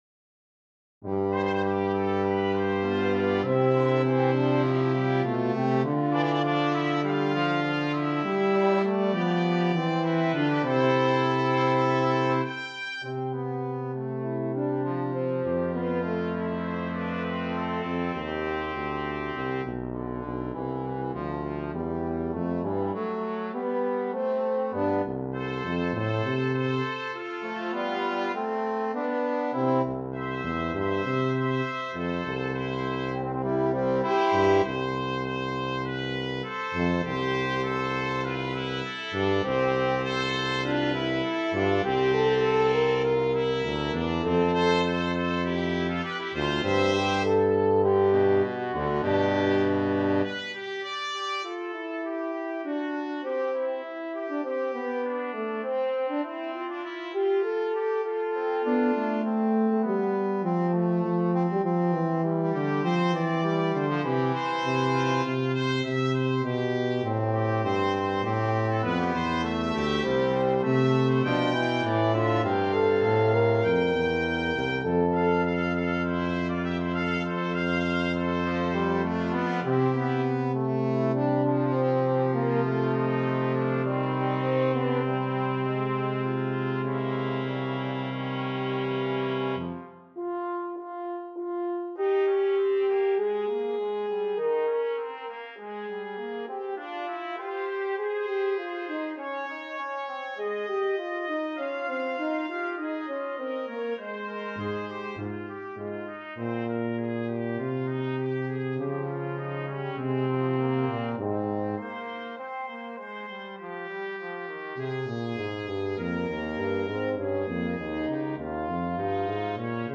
Voicing: Brass Quintet